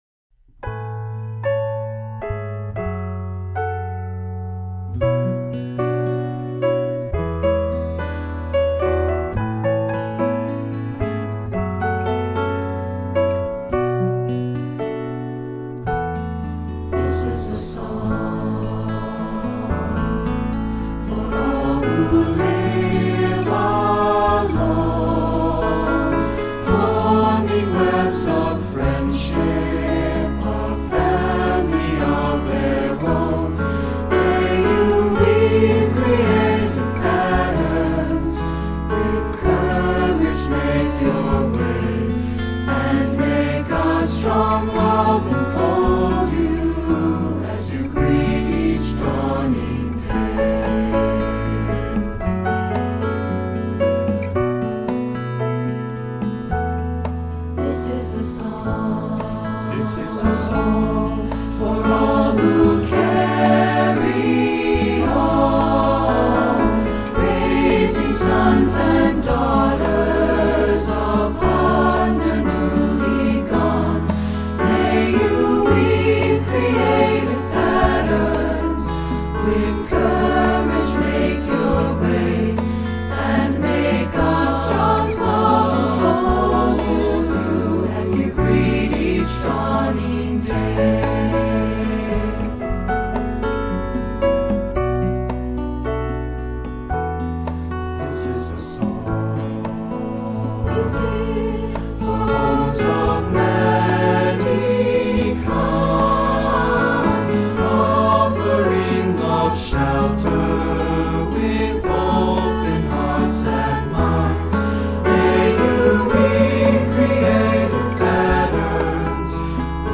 Voicing: Assembly,SAB